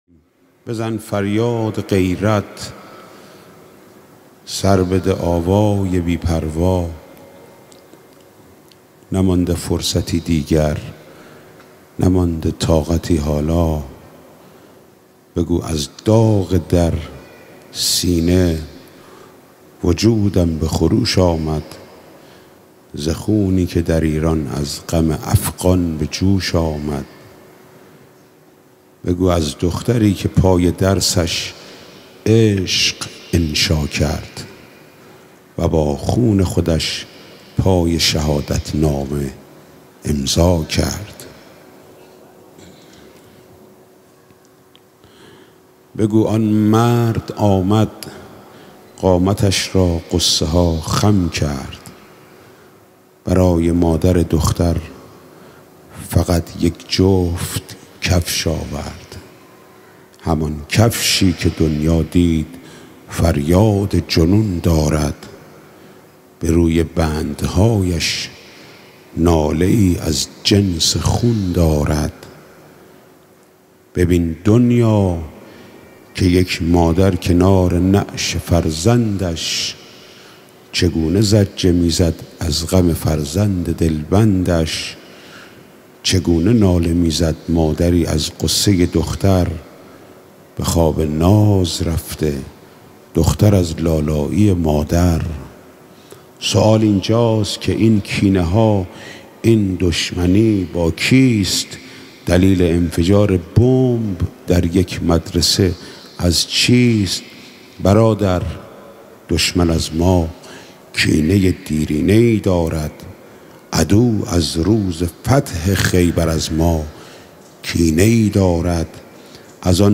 اين قطعه مربوط به مراسم مناجات شب بیست و هشتم ماه رمضان در سال ۱۴۰۰ مى باشد كه در هیأت رایة العباس (عليه السّلام) اجرا شده است.